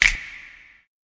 YM - Reverb Snap 1.wav